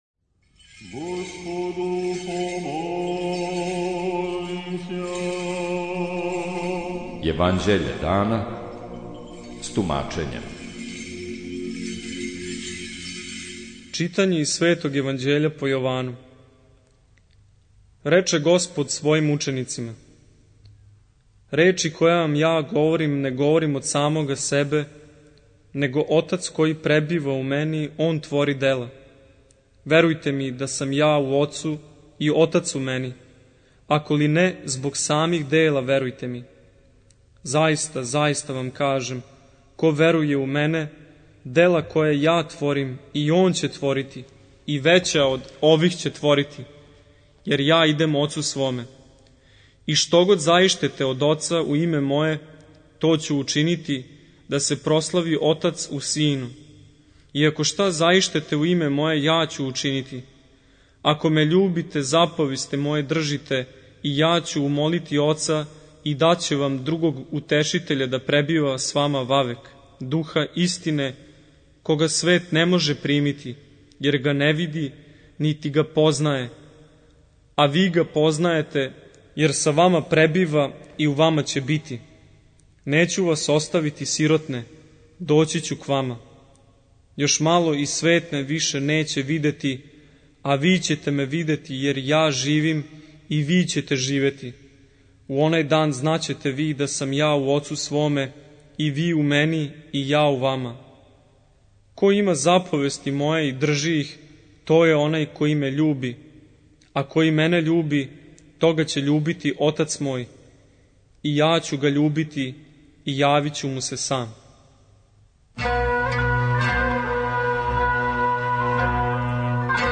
Читање Светог Јеванђеља по Луки за дан 04.02.2023. Зачало 88.